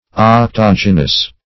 Search Result for " octogynous" : The Collaborative International Dictionary of English v.0.48: Octogynian \Oc`to*gyn"i*an\, Octogynous \Oc*tog"y*nous\, a. (Bot.)